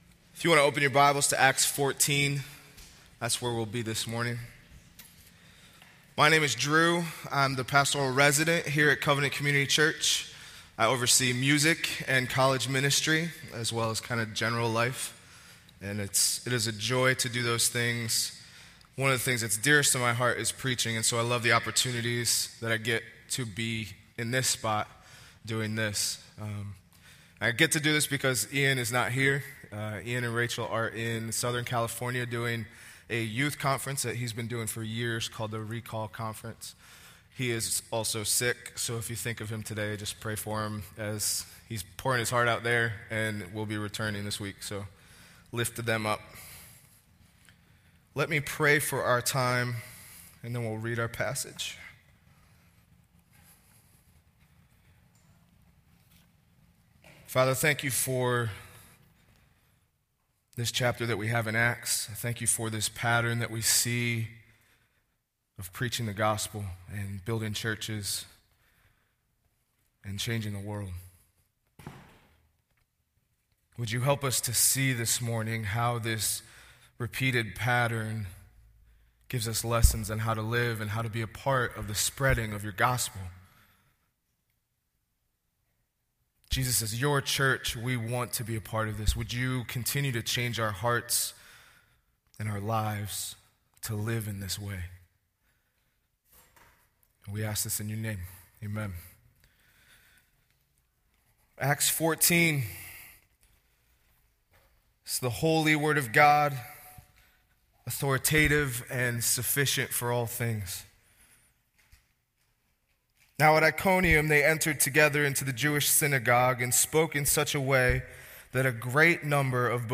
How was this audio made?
Covenant Community Church | West Philadelphia Reformed Charismatic Church